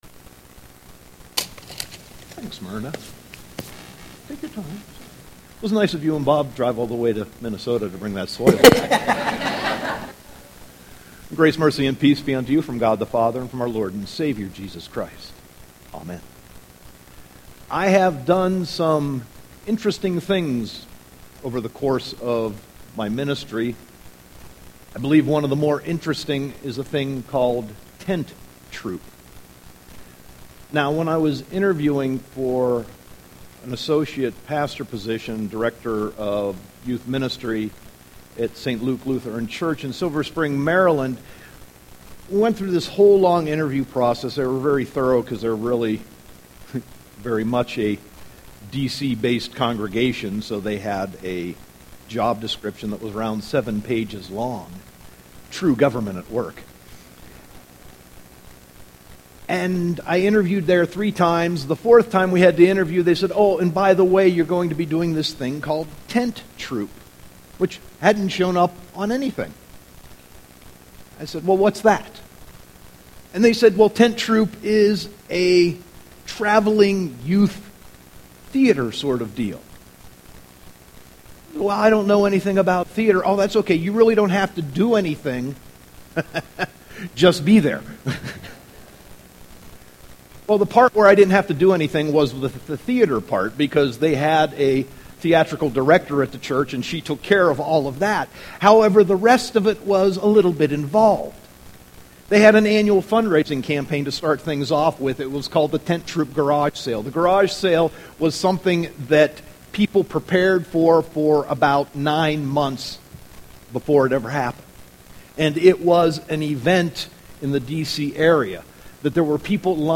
Sermon 7.13.2014